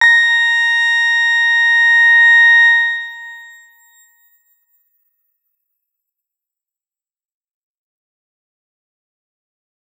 X_Grain-A#5-pp.wav